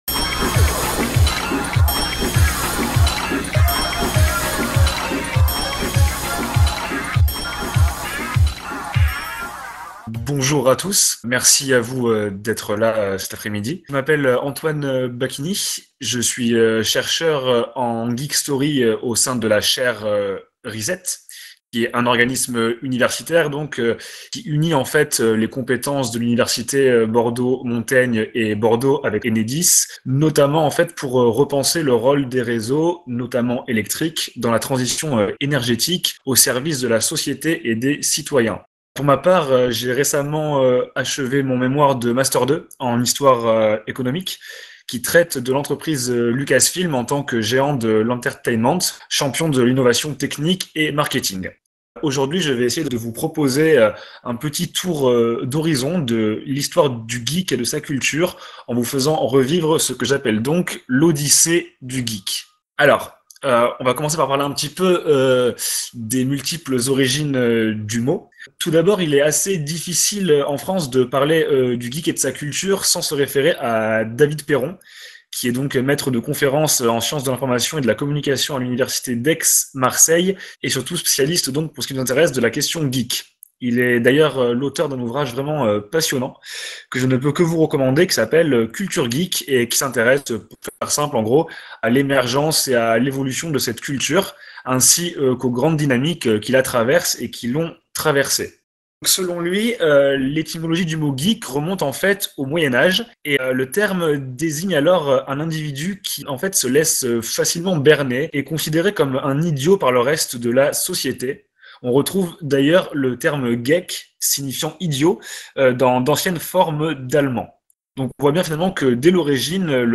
Devenu le Bordeaux Geekfest Virtual Experience le temps d'un week-end, la salle Enedis by Club Arthur Dent a accueilli diverses conférences comme celles proposées par les étudiants-chercheurs et partenaires de la Chaire RESET, partenaire pionnier de l'événement.